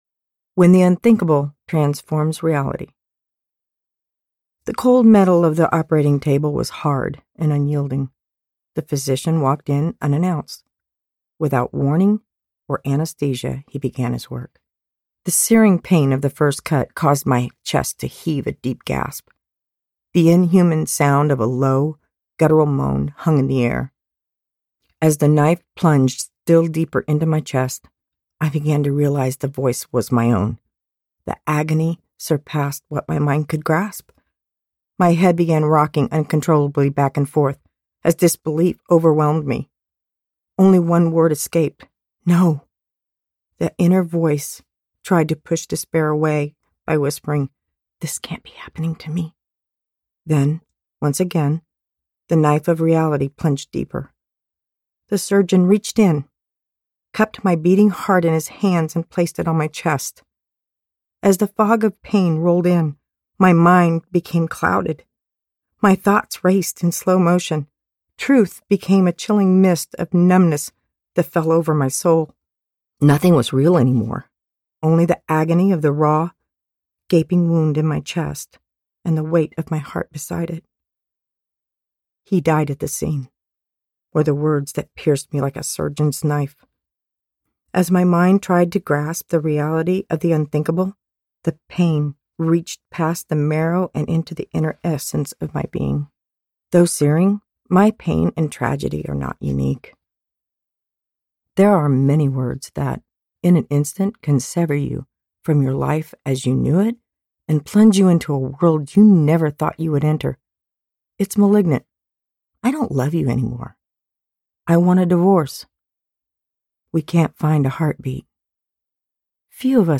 Freefall Audiobook
Narrator
6.0 Hrs. – Unabridged